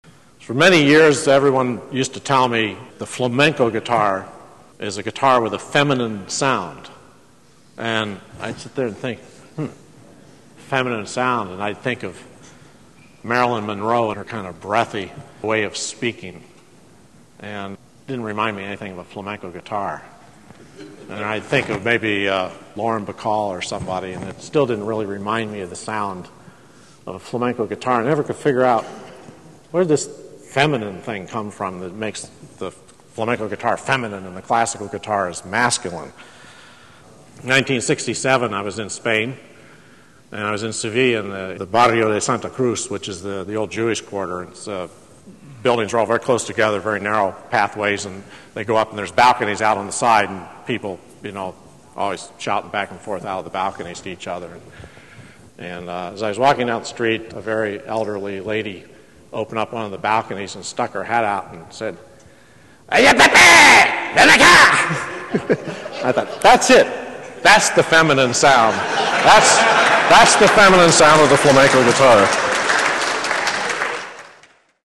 speaking at the 2001 GAL Convention.